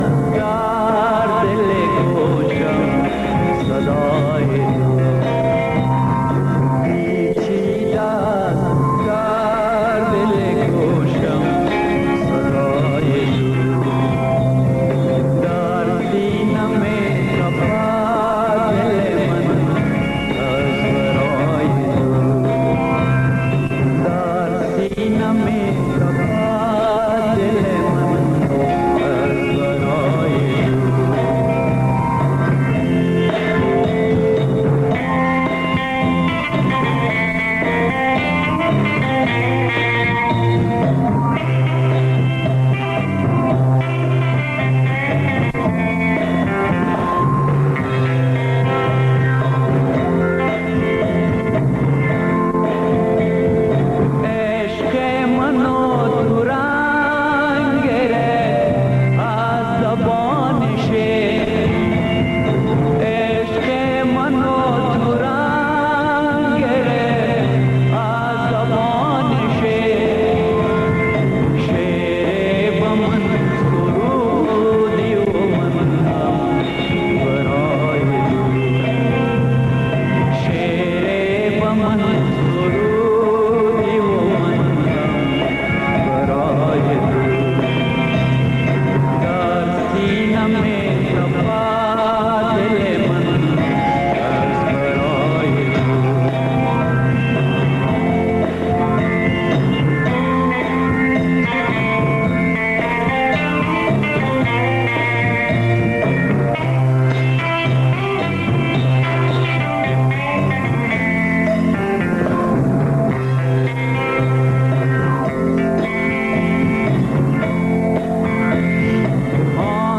خبرهای کوتاه - میز گرد (تکرار)